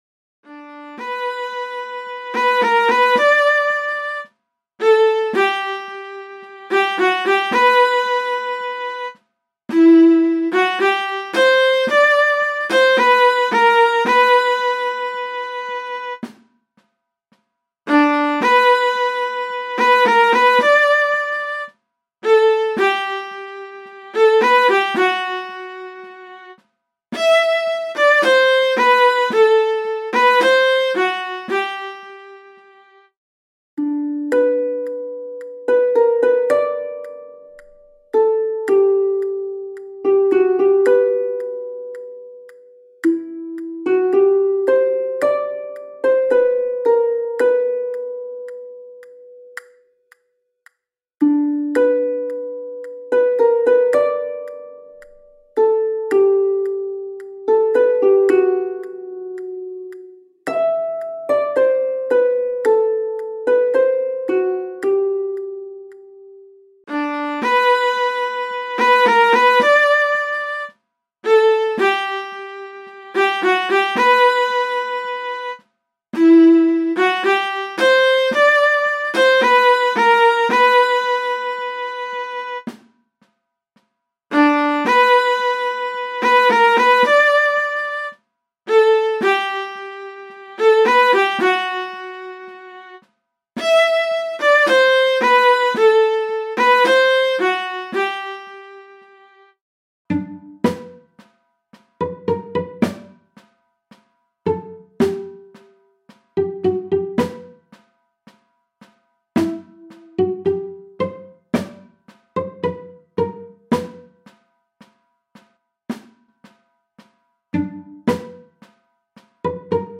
Sie hat zwar einen für Wanderlieder sehr untypischen Tangorhythmus, doch das Versmaß des Gedichtes brachte mich immer wieder dahin zurück.
midi_zum-tor-hinaus_alt_1-stimmig_128.mp3